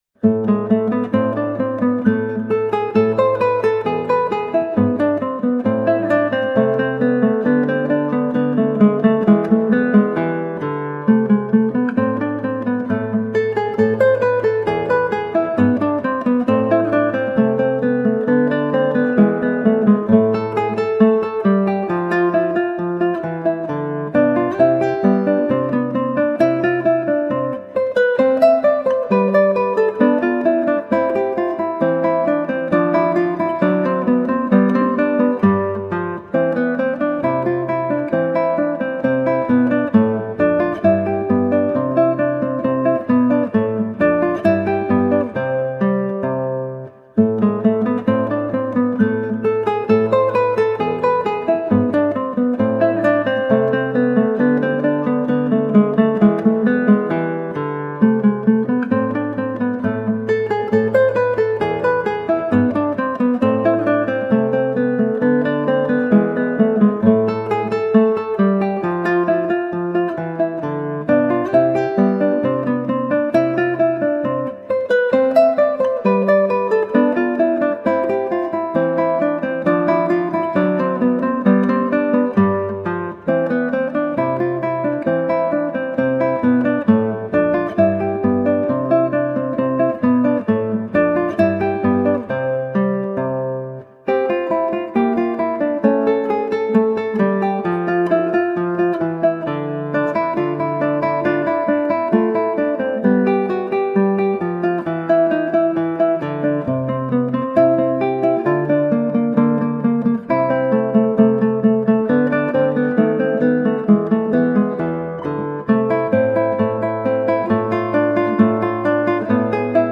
Colorful classical guitar.
Classical, Baroque, Instrumental
Classical Guitar